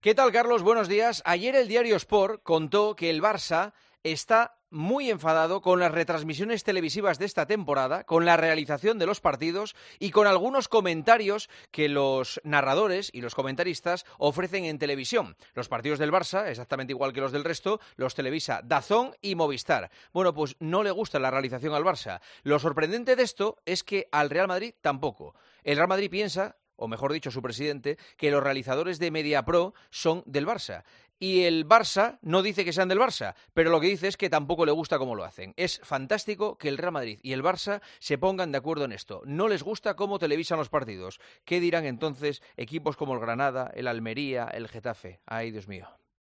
Carlos Herrera y Juanma Castaño
Escucha el comentario de Juanma Castaño en Herrera en COPE este martes 10 de octubre de 2023